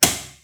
switchclunk.wav